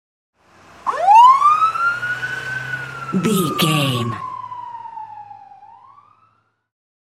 Ambulance Ext Passby Large Siren Turn on
Sound Effects
urban
chaotic
emergency